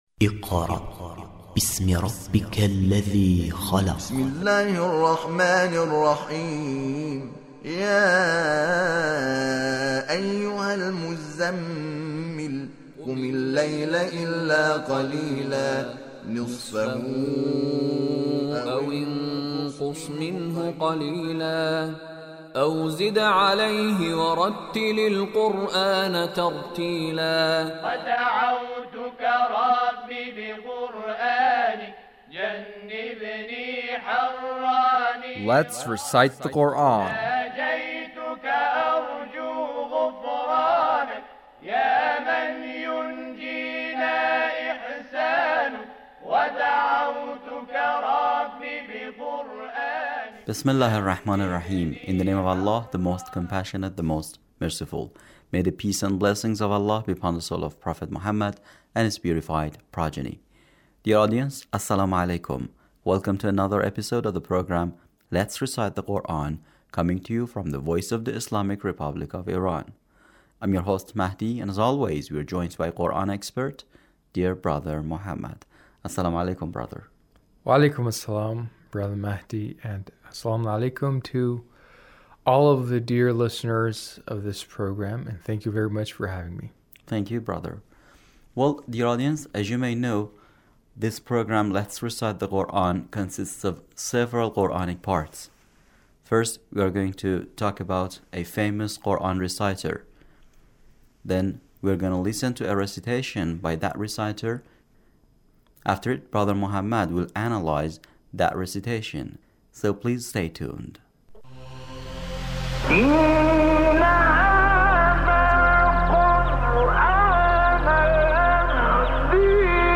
Recitation of Maryam by Abdul Fattah Sha'sha'i